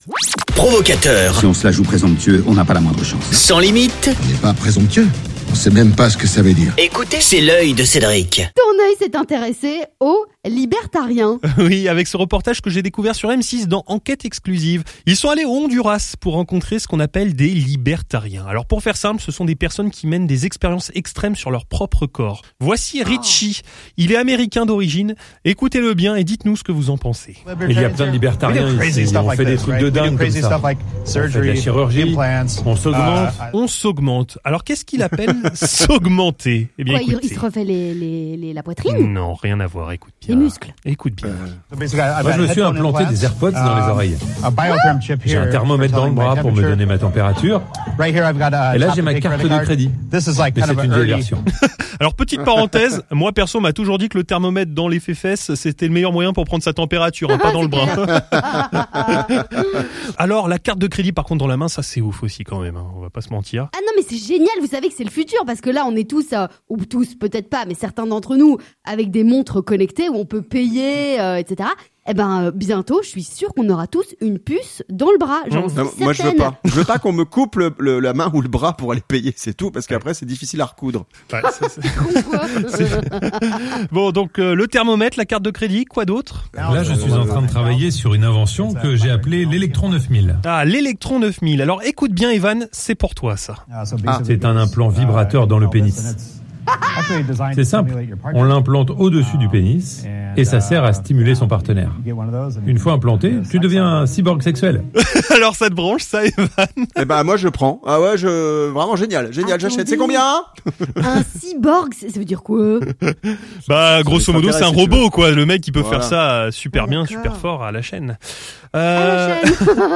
Reportage sur les libertariens...